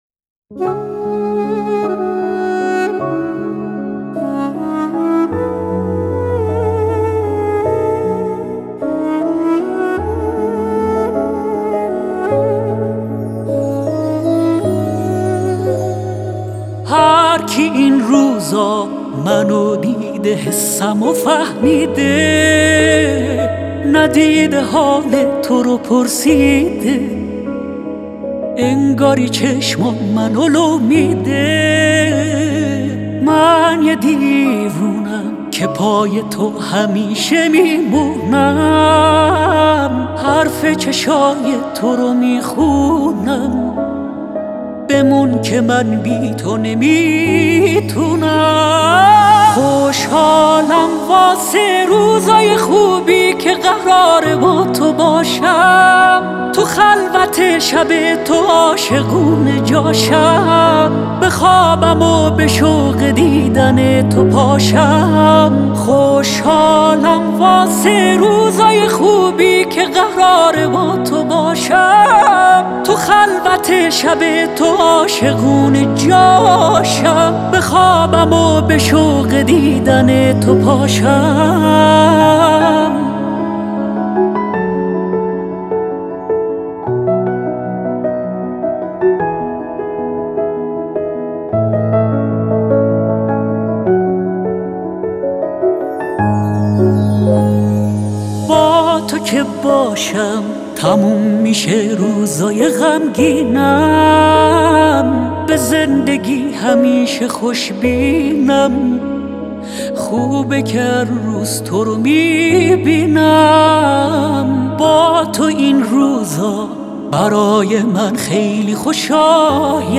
تک آهنگ
پاپ